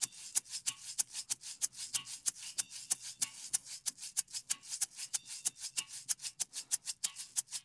SLB SHAKER L.wav